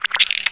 1 channel
chatter2.wav